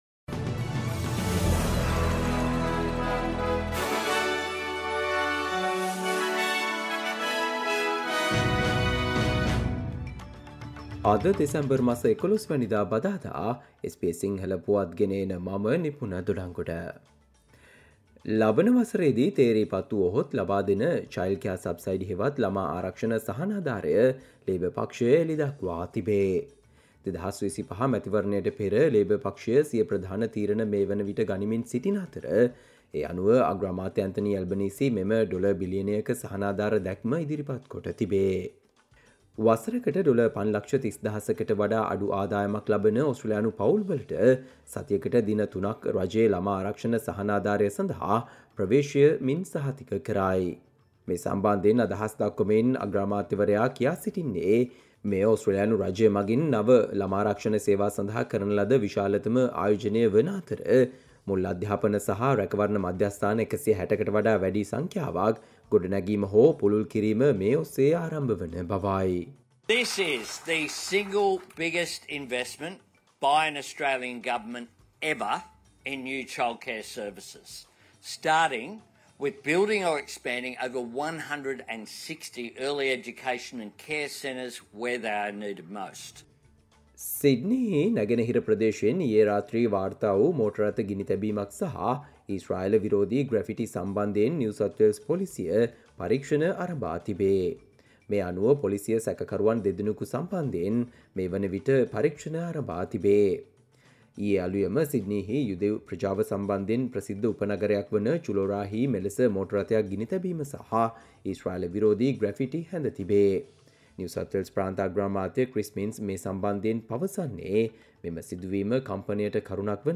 SBS Sinhala Newsflash 11 December 2024: Labor promises three days subsidised child care if re-elected